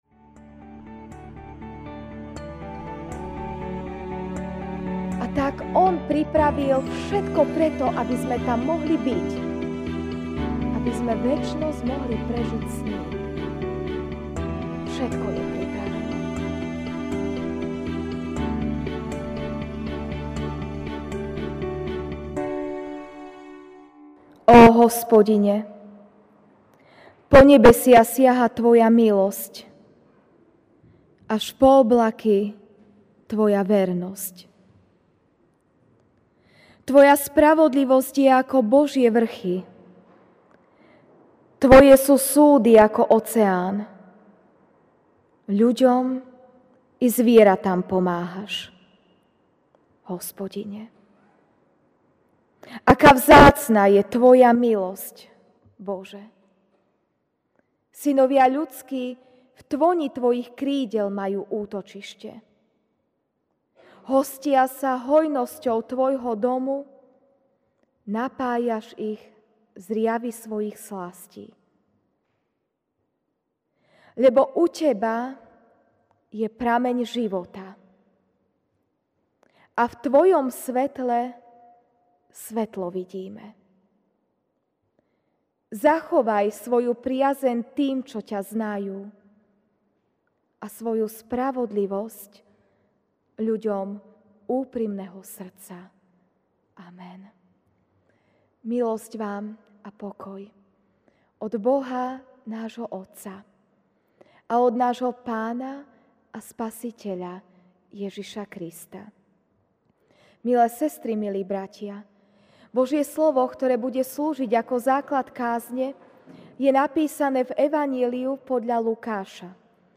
Večerná kázeň